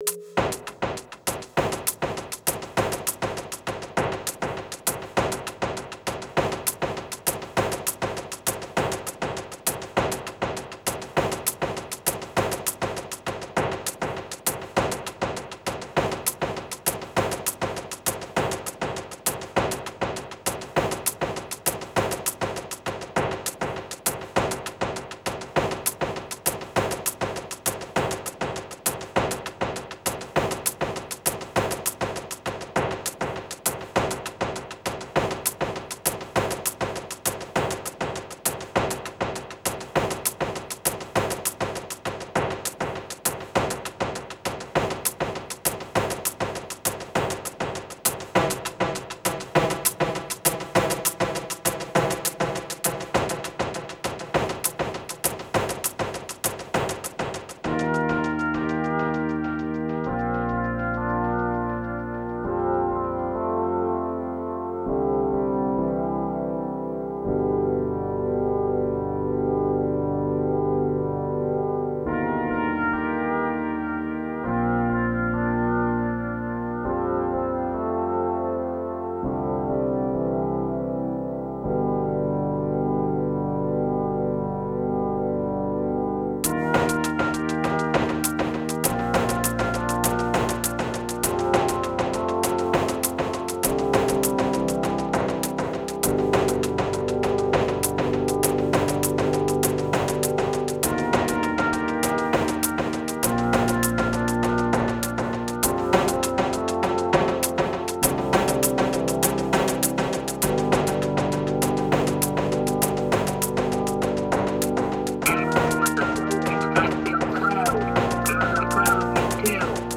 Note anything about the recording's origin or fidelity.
a CD quality rip from the same CD